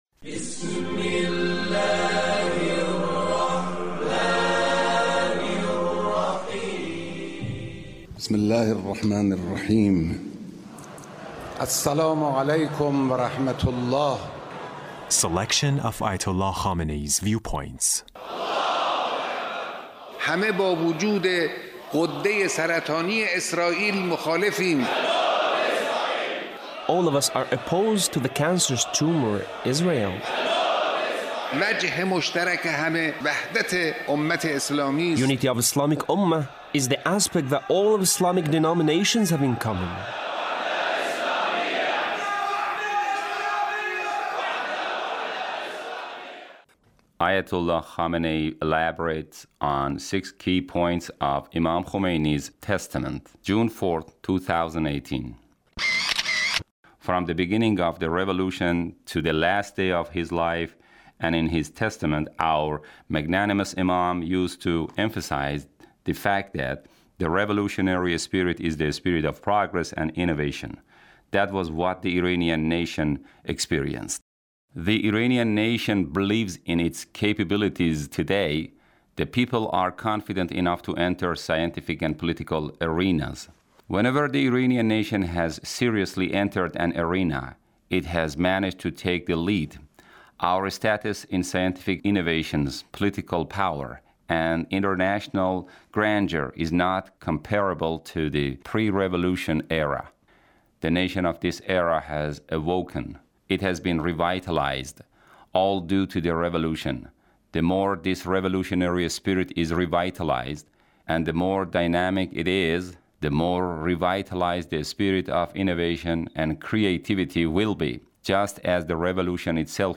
Leader's Speech (1750)